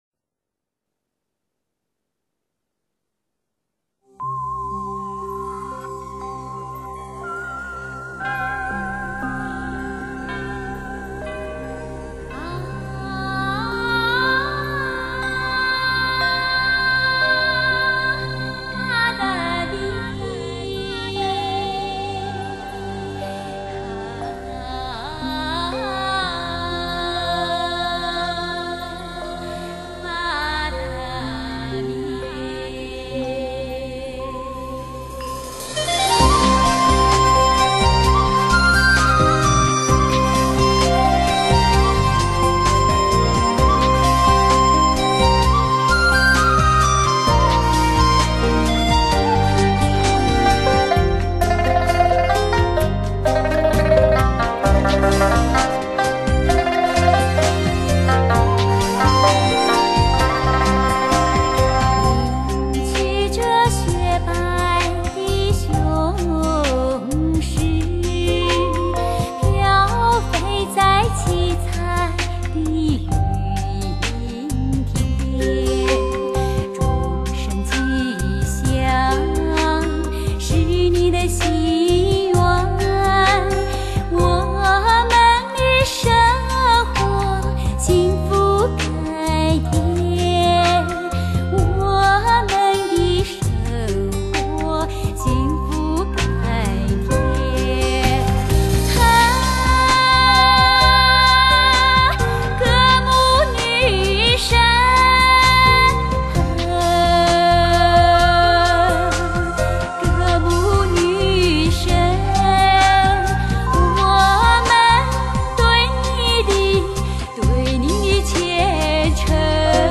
清新美妙的天籁之音，
如蝉声、像鸟鸣，似溪流一样清澈，如天空一样湛蓝……